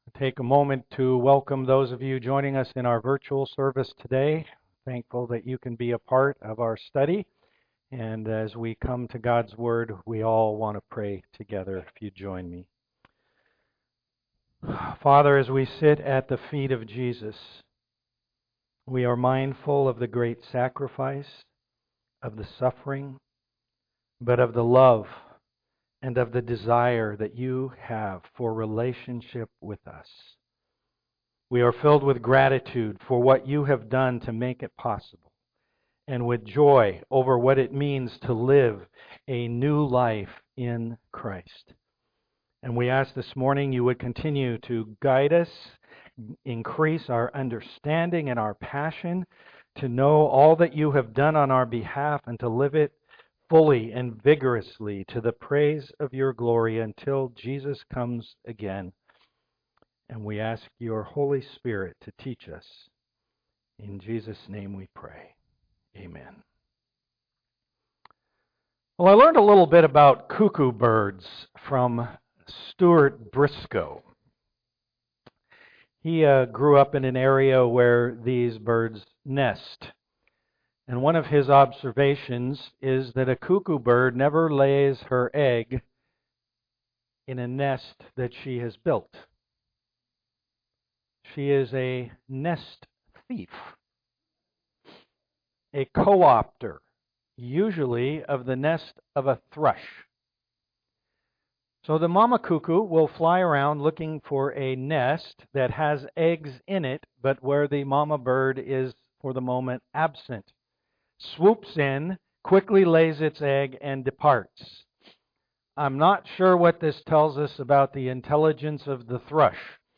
Romans 8:12-17 Service Type: am worship We all struggle with the desires of our flesh.